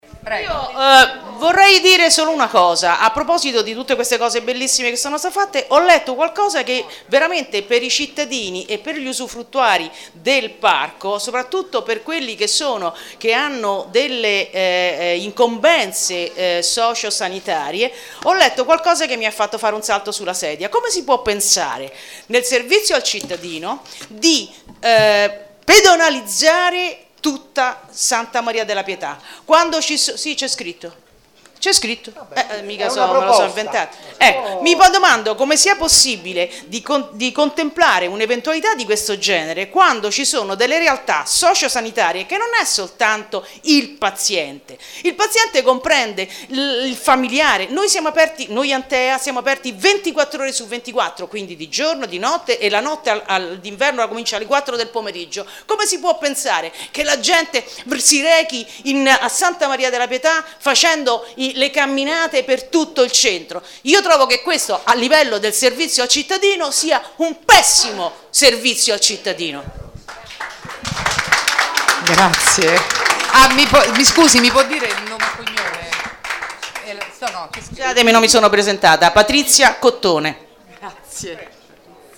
Interventi dei cittadini